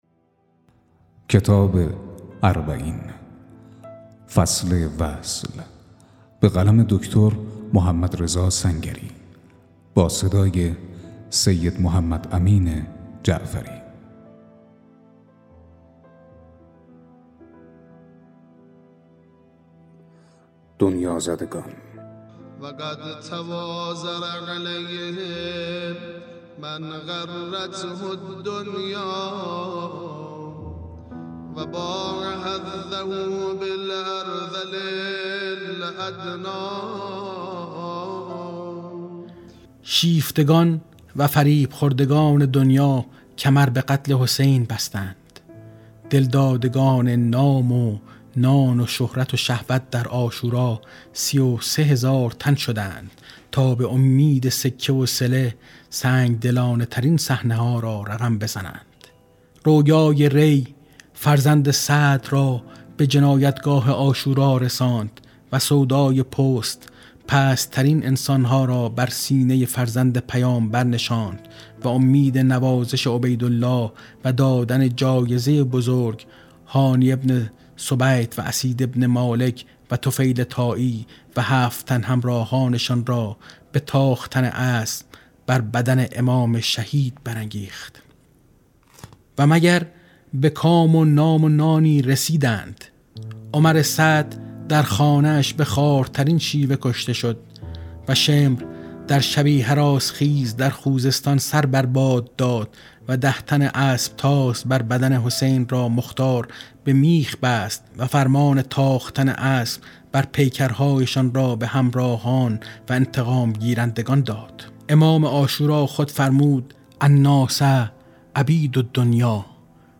🔻ضبط و آماده‌سازی: استودیو همراز